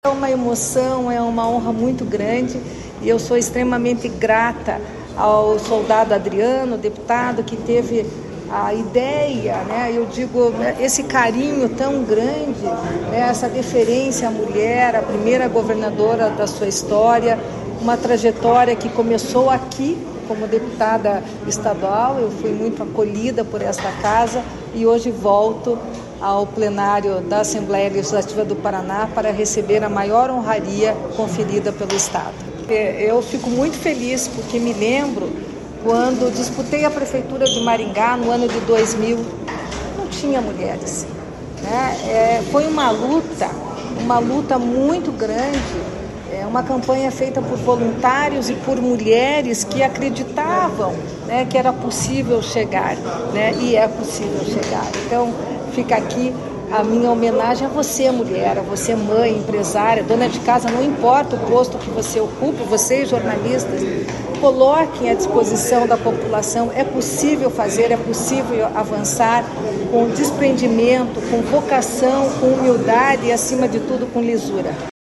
Sonora da ex-governadora Cida Borghetti sobre o título de cidadã honorária do Paraná que recebeu na Alep